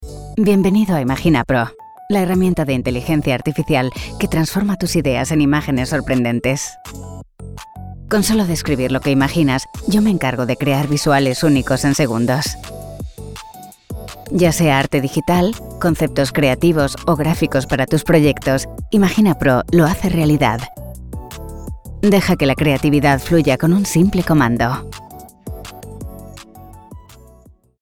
Versatile, Elegant, Sincere, Warm tones. 30-40.
Warm, Informative, Conversational